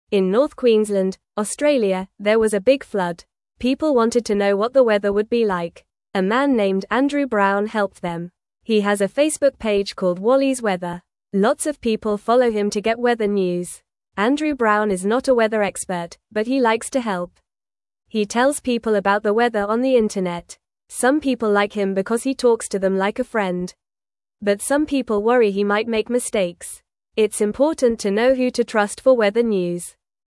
Fast
English-Newsroom-Beginner-FAST-Reading-Helping-Friends-with-Weather-News-in-Australia.mp3